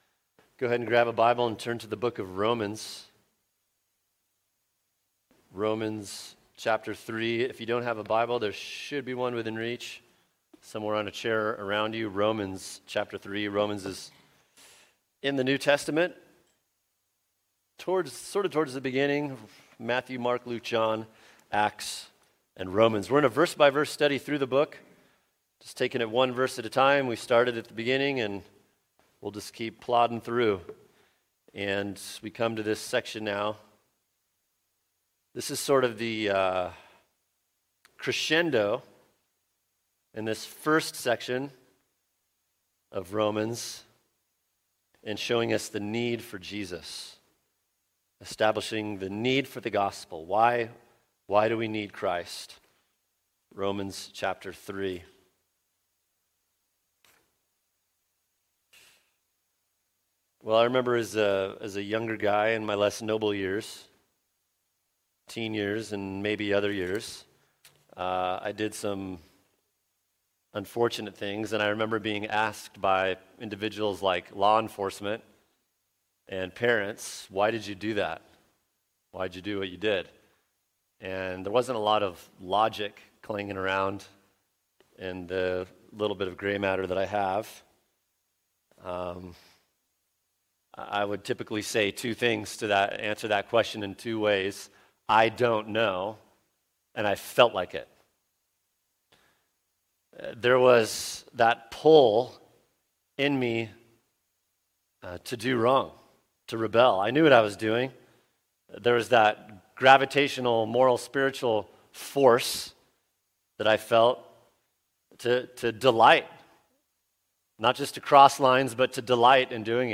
[sermon] Romans 3:10-12 The Catastrophe Of Our Depravity | Cornerstone Church - Jackson Hole